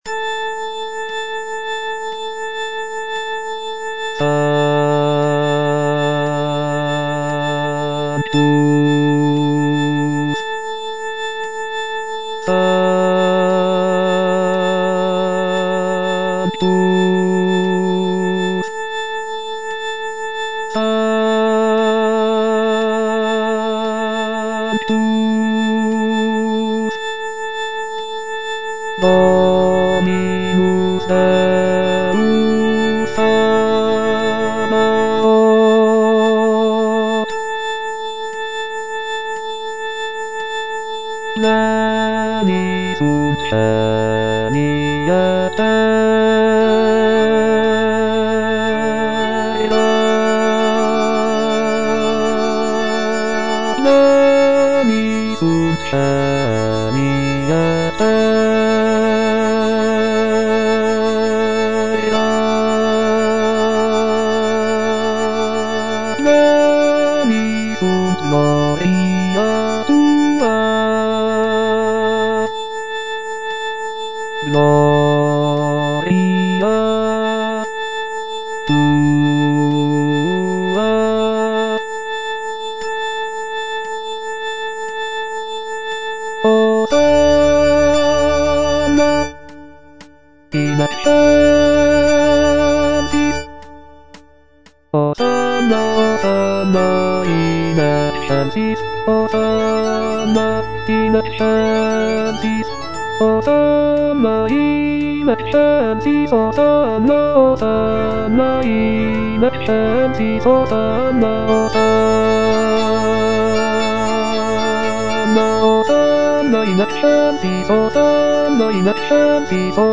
F. VON SUPPÈ - MISSA PRO DEFUNCTIS/REQUIEM Sanctus (bass I) (Voice with metronome) Ads stop: auto-stop